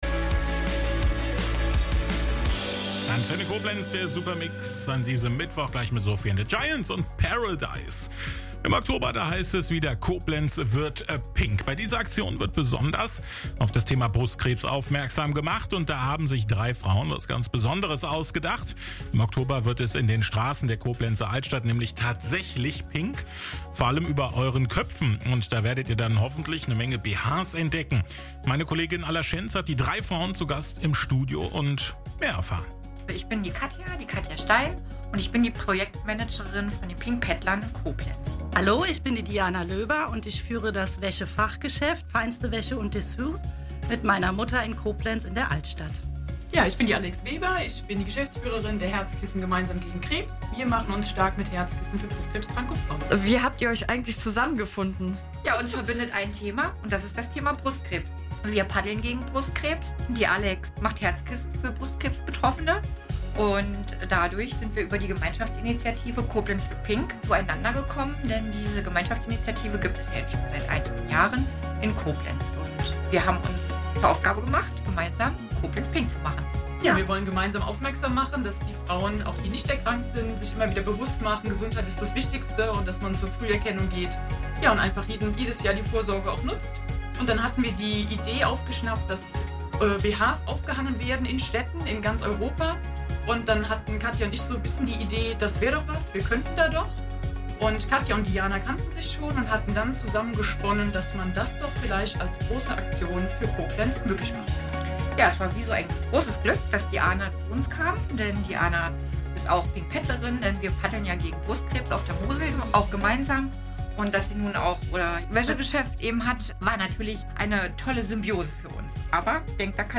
Radiointerview Juni 2025 BH-Aktion von Koblenz wird pink